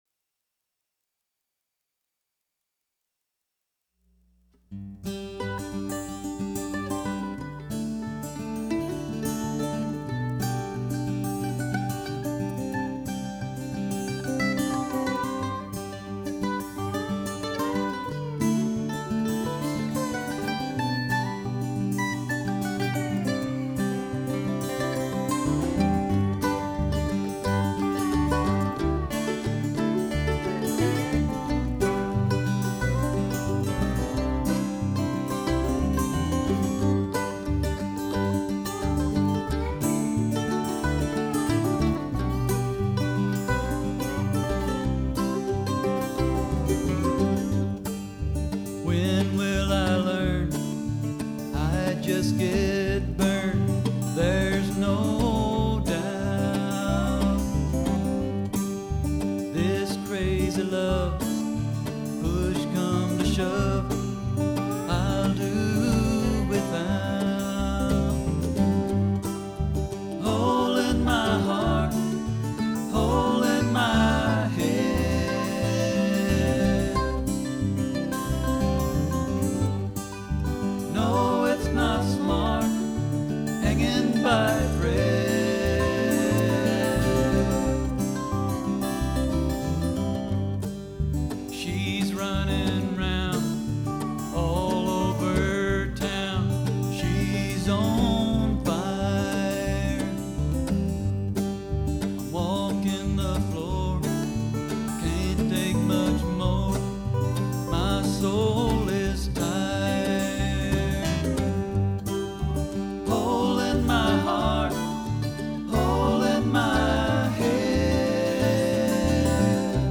These are a couple of tunes I recently recorded using a BR-8 digital recorder.
Just a few guitars, keyboard, and effects.